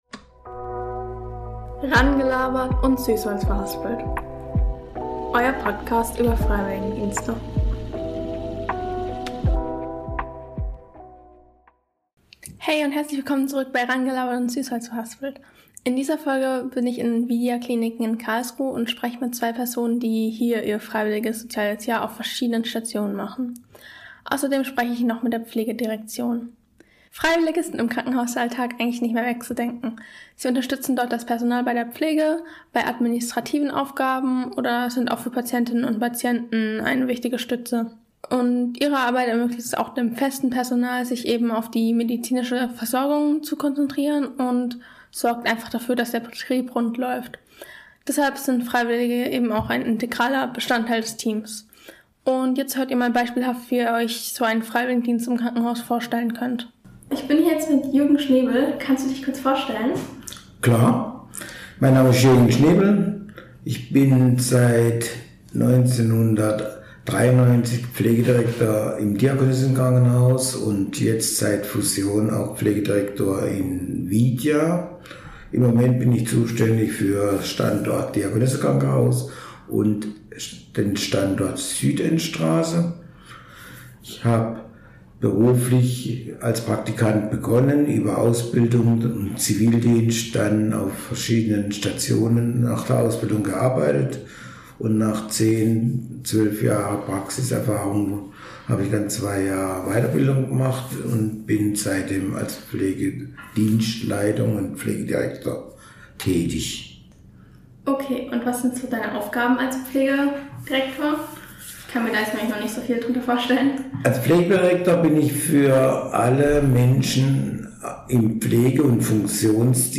Beschreibung vor 9 Monaten In dieser Folge habe ich die ViDia Kliniken in Karlsruhe besucht.